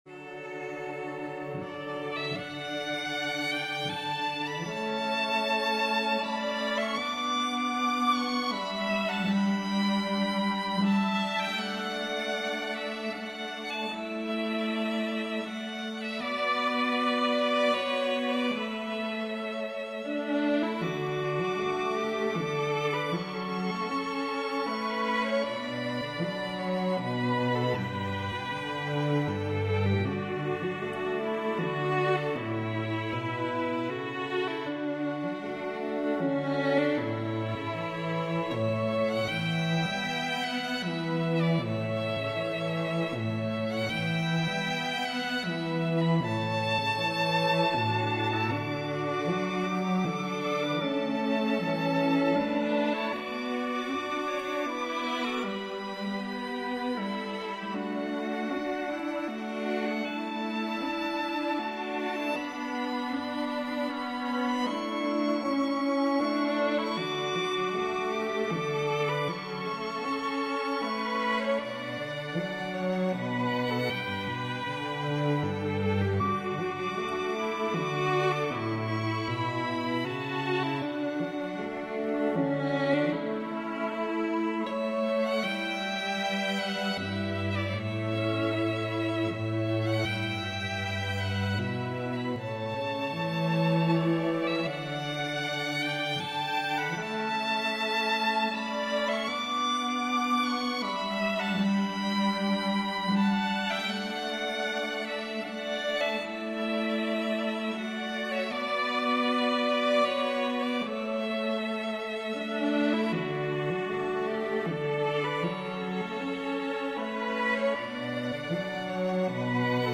String Quartet